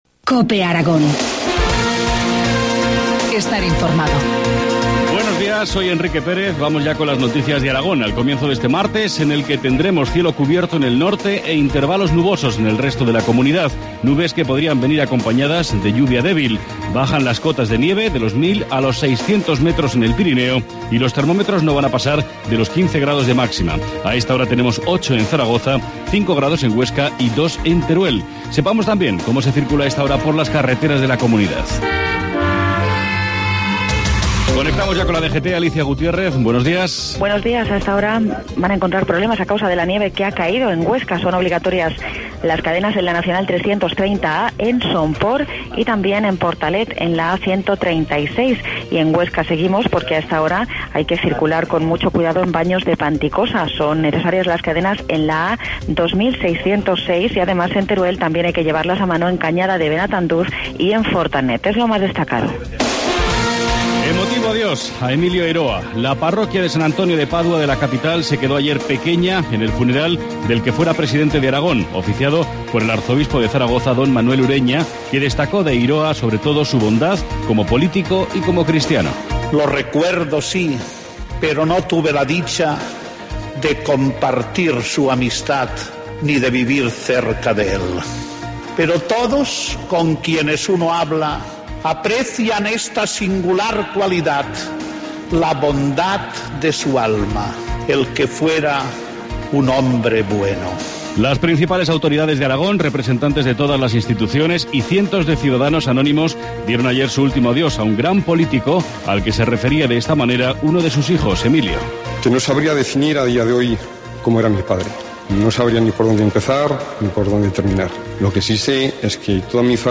Informativo matinal, martes 12 de marzo, 7.25 horas